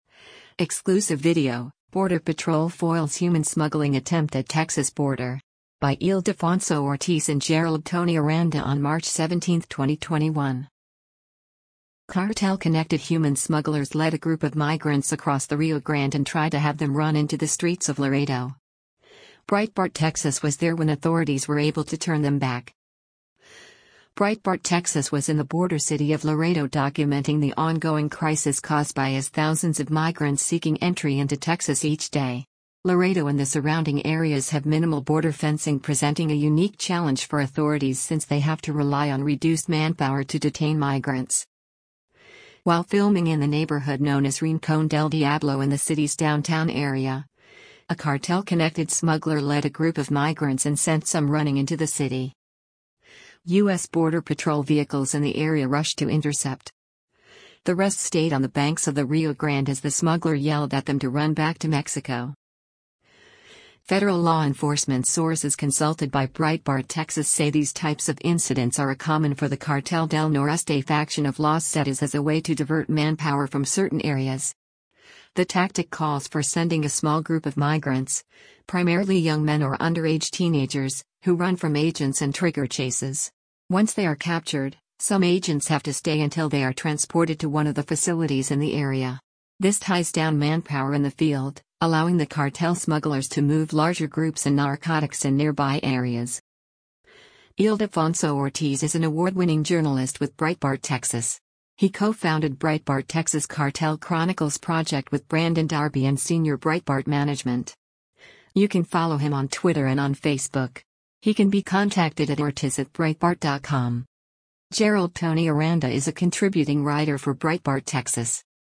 While filming in the neighborhood known as Rincon Del Diablo in the city’s downtown area, a cartel-connected smuggler led a group of migrants and sent some running into the city.
The rest stayed on the banks of the Rio Grande as the smuggler yelled at them to run back to Mexico.